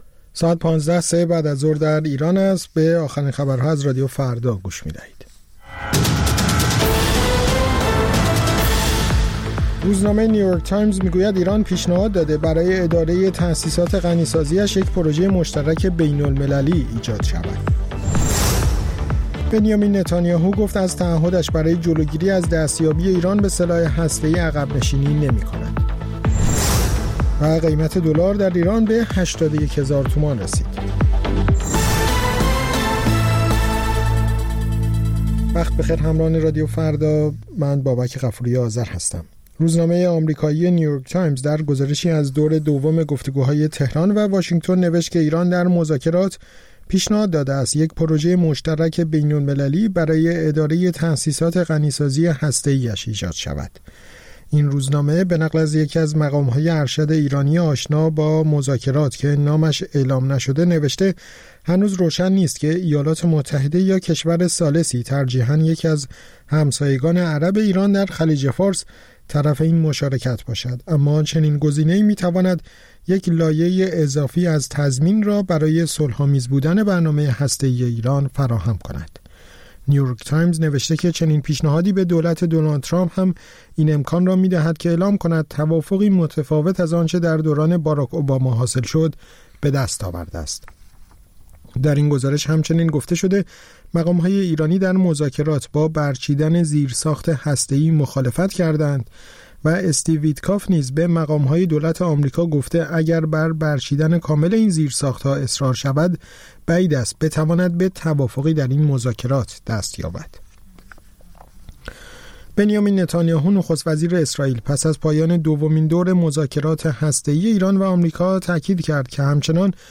سرخط خبرها ۱۵:۰۰